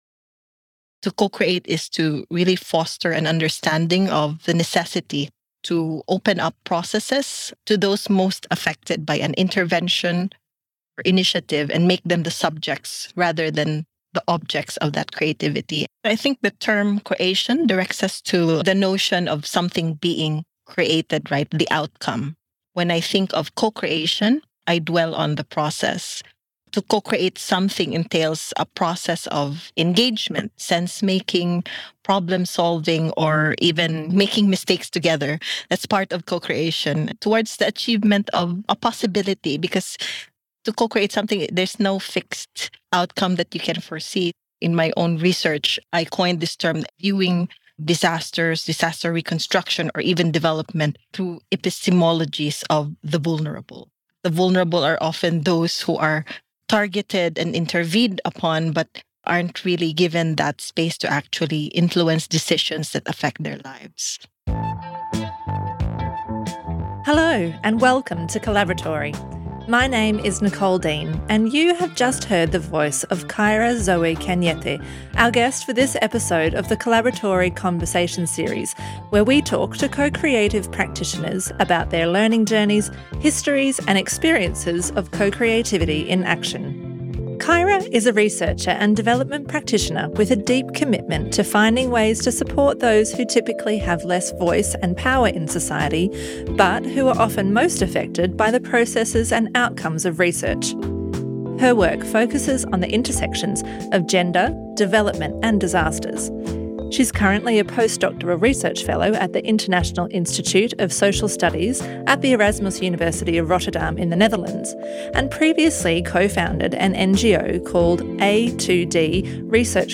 Co-created Research: A Conversation